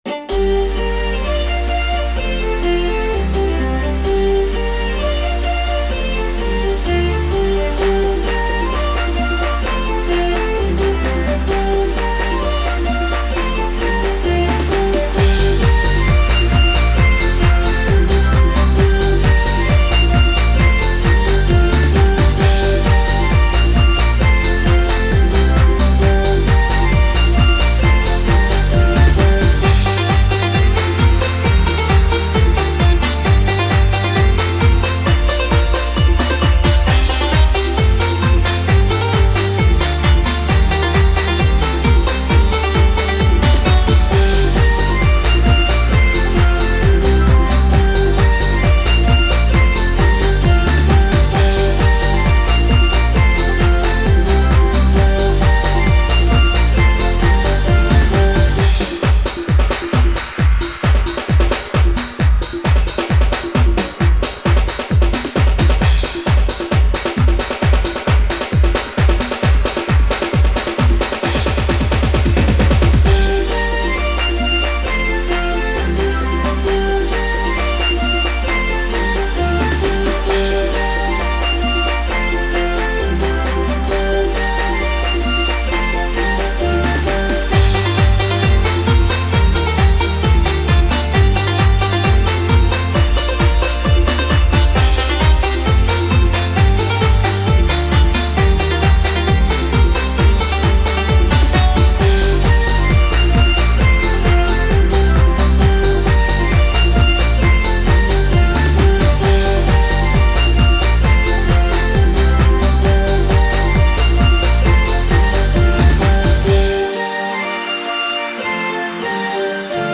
BPM131-134
Audio QualityPerfect (High Quality)